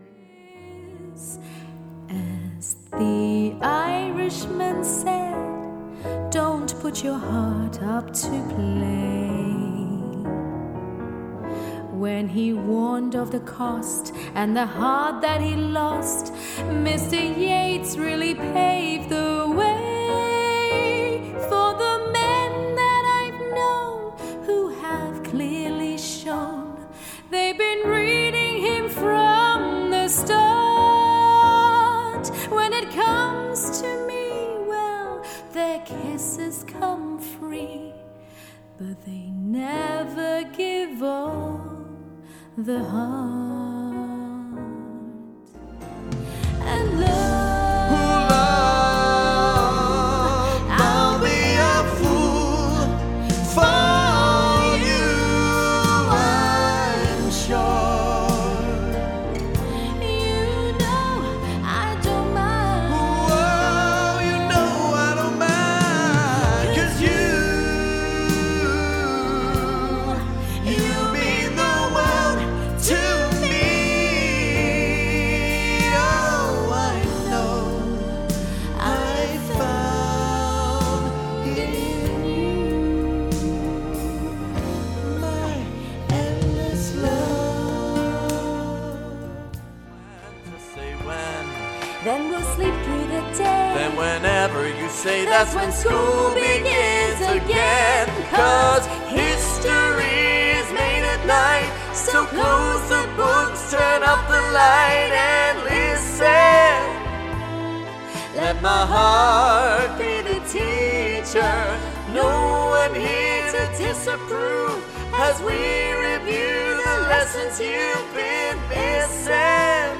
Female
Singing
Duo Singing In English